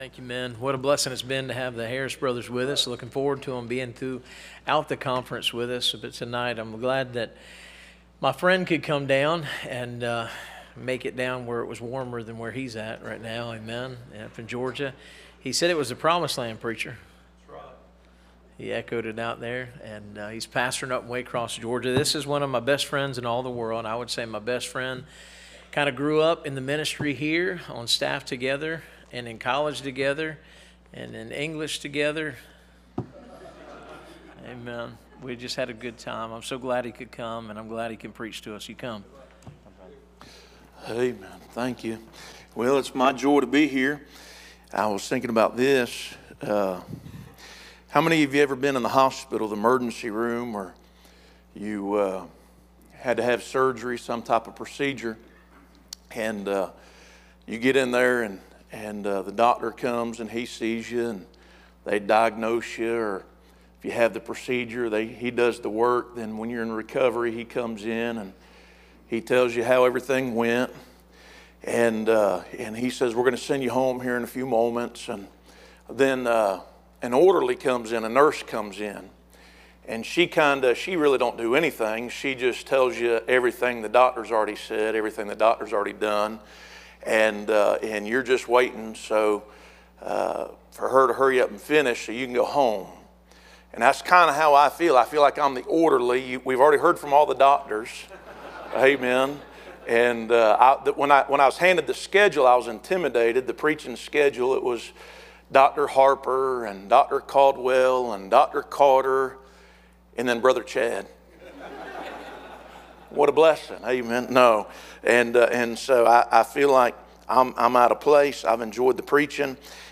Series: 2025 Bible Conference
Preacher